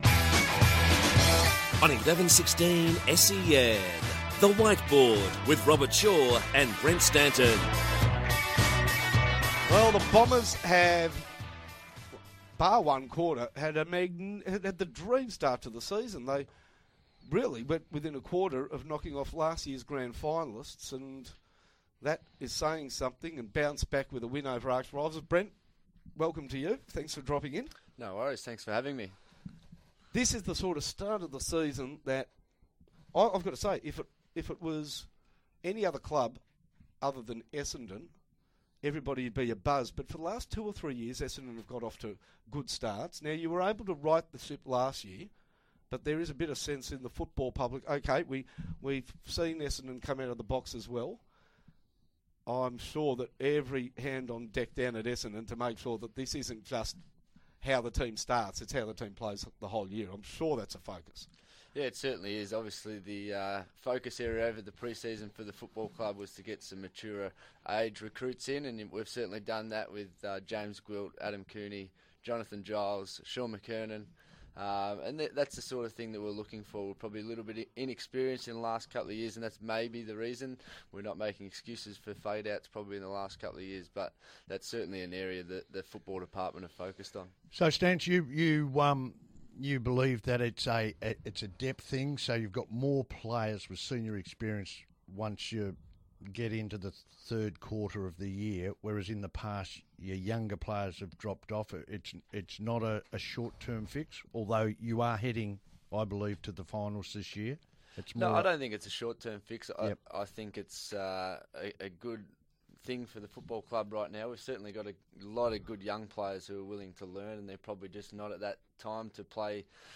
in studio to provide an in-depth look behind the scenes of the modern day AFL player.